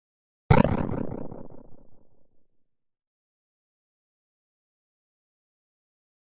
Liquid Hit Liquid Thump with Tremolo, Reverb